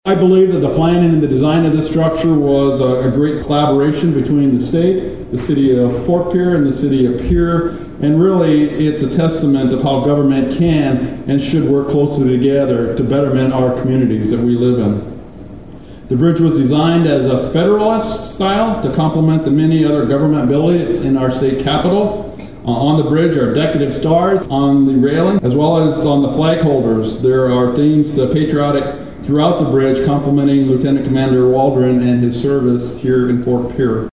Various local, state and federal dignitaries gathered on Tuesday (March 25, 2025) to hold the official ribbon cutting ceremony for the new Lieutenant Commander John C. Waldron Memorial Bridge over the Missouri River between Fort Pierre and Pierre.